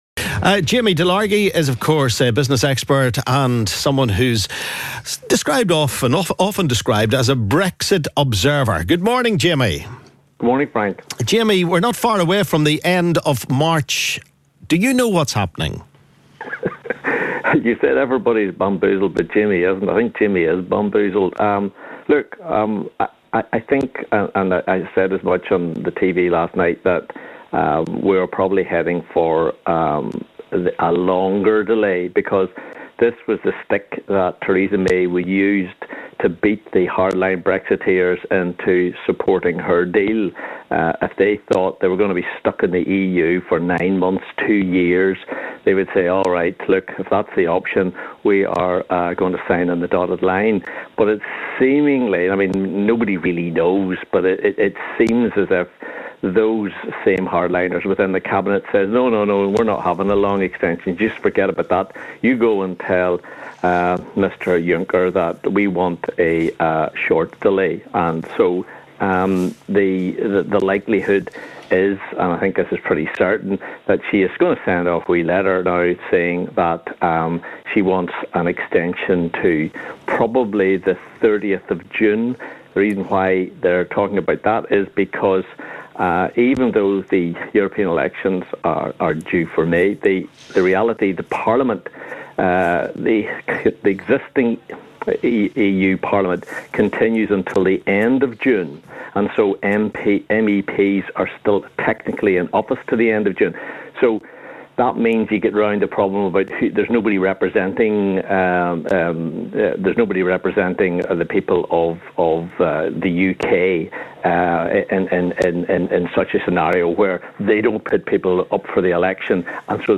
the listeners have their say